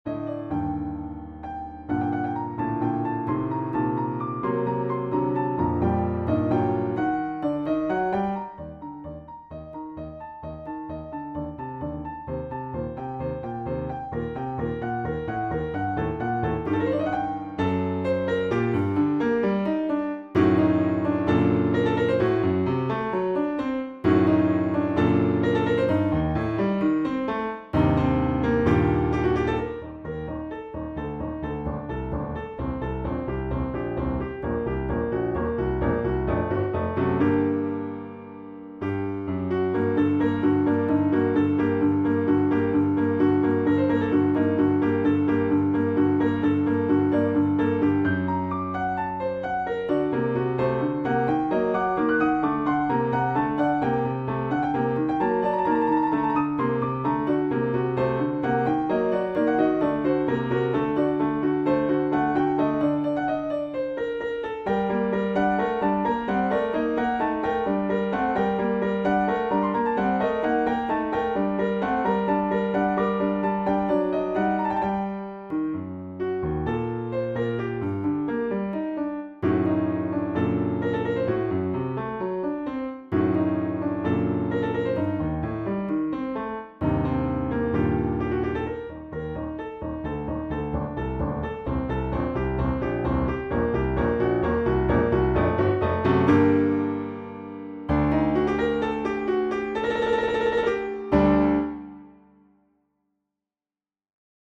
For Solo Piano
Solo Piano 1:45 Arabic Classical Fusion